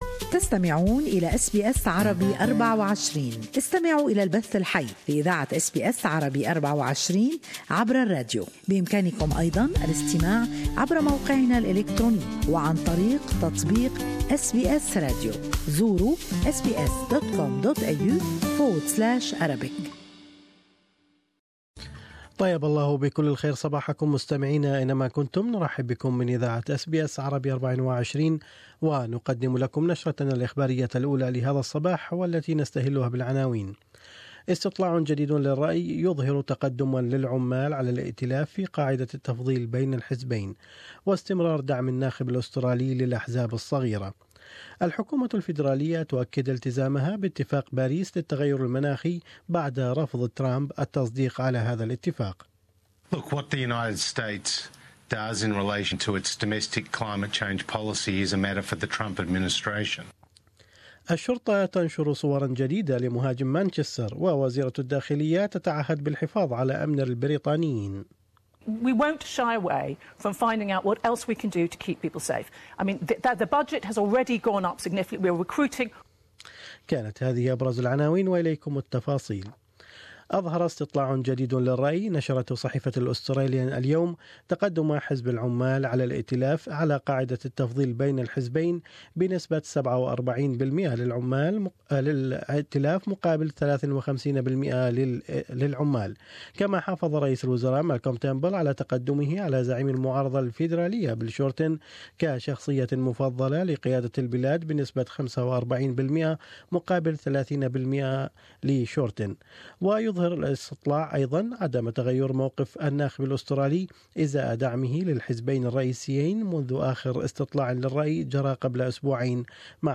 Australian and world news in morning news bulletin.